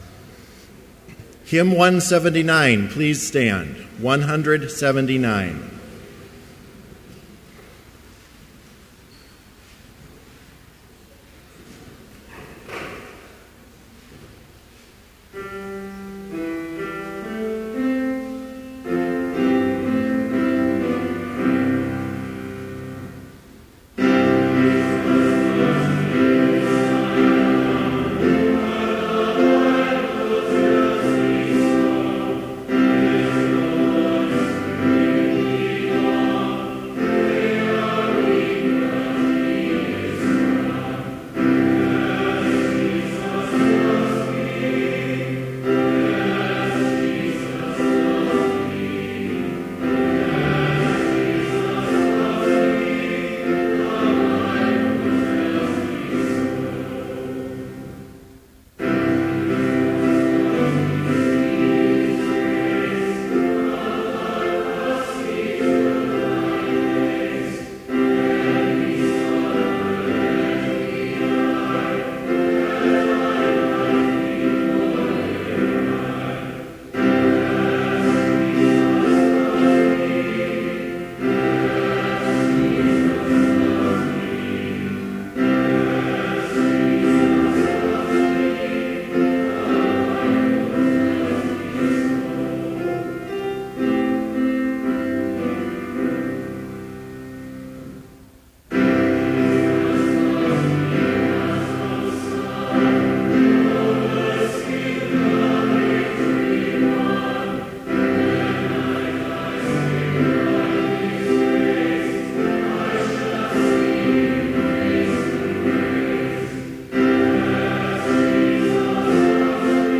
Complete service audio for Chapel - February 25, 2014